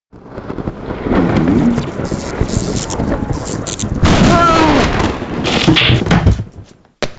Talking Ben Labs Lightening Sound Effect Free Download